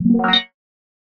alert07.ogg